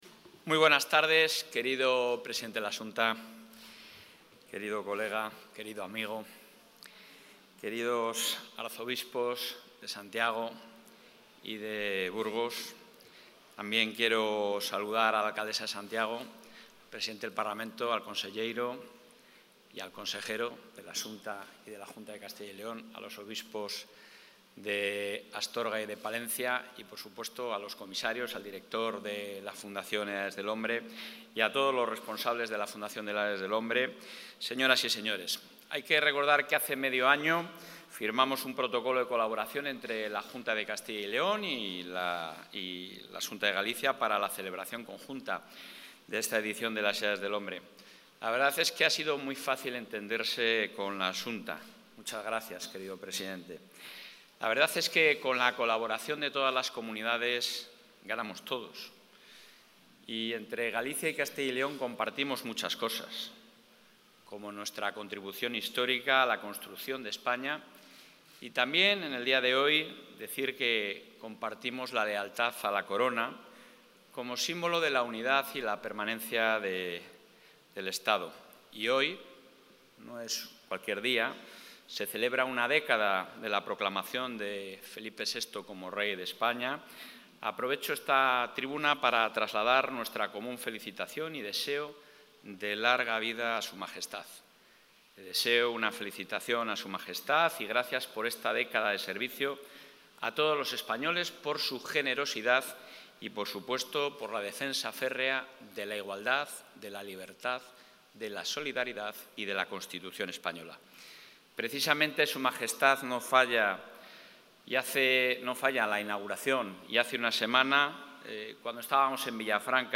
Intervención del presidente de la Junta.
El presidente de la Junta de Castilla y León, Alfonso Fernández Mañueco, ha participado en el acto de inauguración de la XXVII edición de las Edades del Hombre `Hospitalitas. La Gracia del Encuentro / A Graza do Encontro”, en Santiago de Compostela. Durante su intervención, ha destacado la importancia de esta muestra, ya que constituye un importante motor económico, promueve el desarrollo del territorio y contribuye a la dinamización, especialmente en el medio rural.